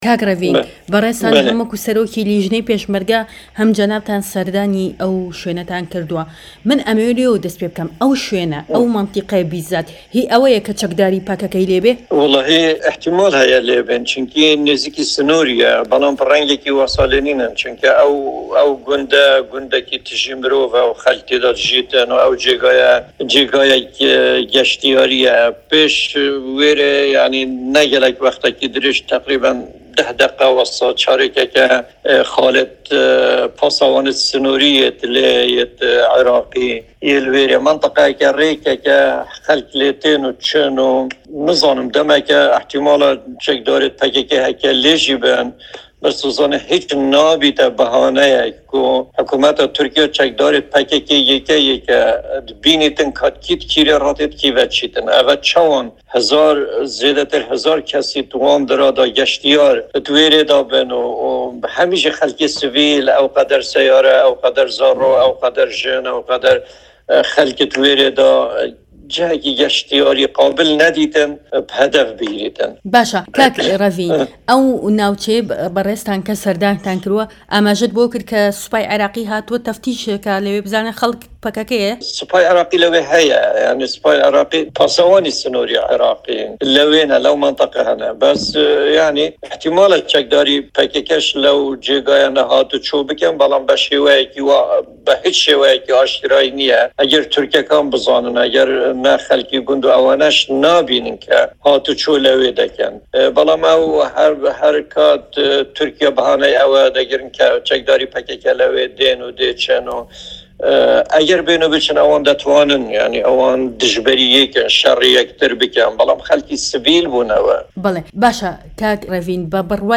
دەقی وتووێژەکەی ڕێڤینگ هروری لەم فایلە دەنگیەدا